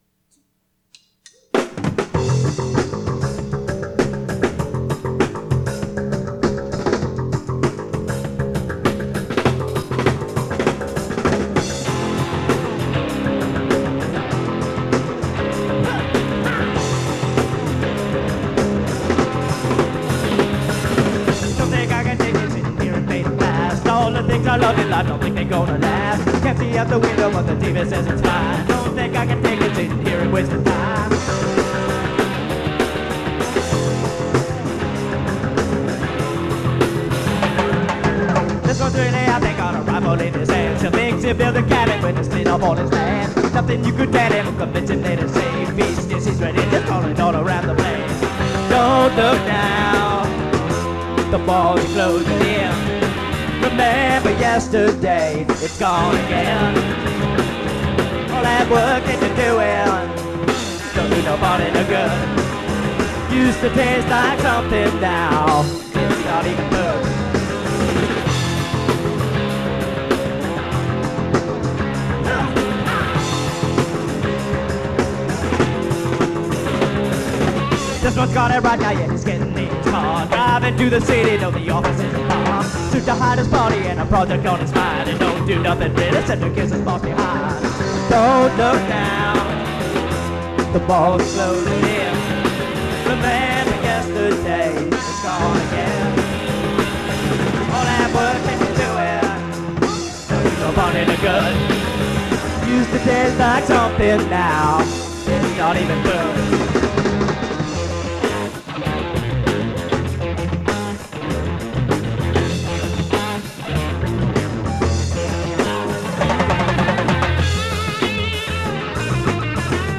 Late 80’s Power Pop/Punk band
Bass, Lead Vocals, Artwork
Drums, Backing Vocals
Guitar, Backing Vocals